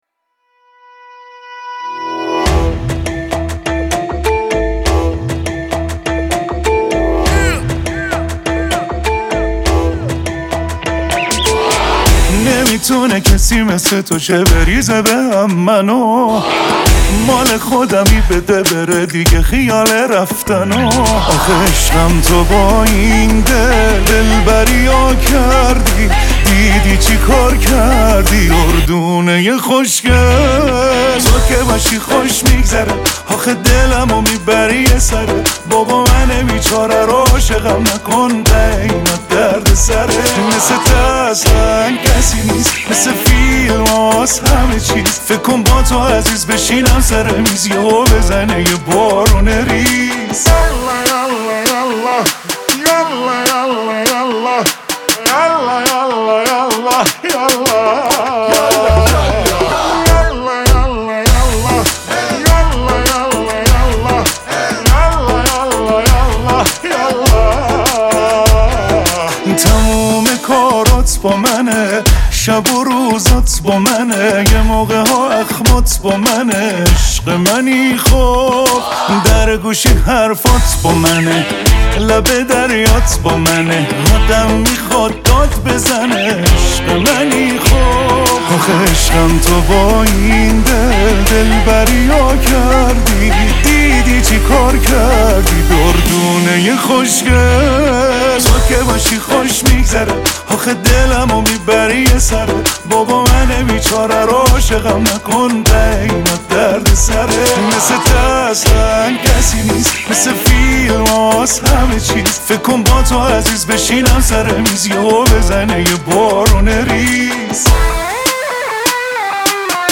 دانلود آهنگ شاد